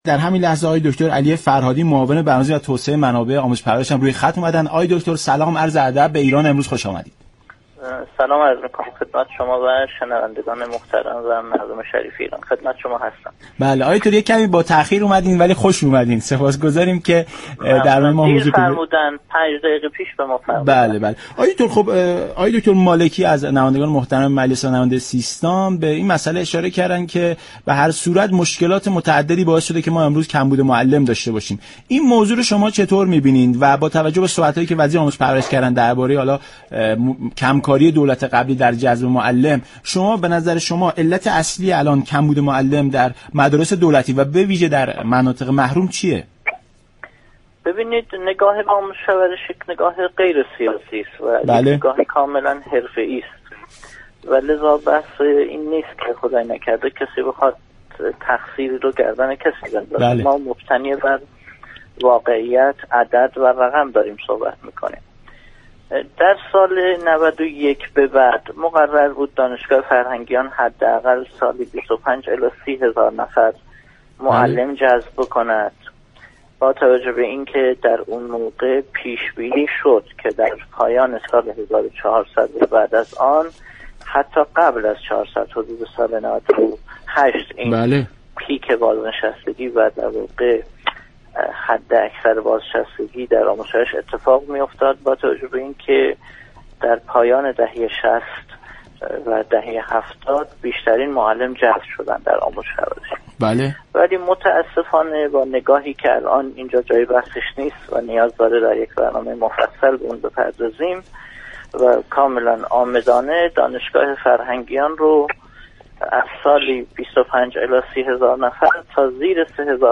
به گزارش شبكه رادیویی ایران، دكتر علی فرهادی معاون برنامه ریزی و توسعه منابع آموزش و پرورش در برنامه ایران امروز درباره علت كمبود معلم در كشور گفت: آموزش و پرورش نهاد غیر سیاسی است و فعالیت خود را مبتنی بر واقعیت و عدد و رقم انجام می دهد.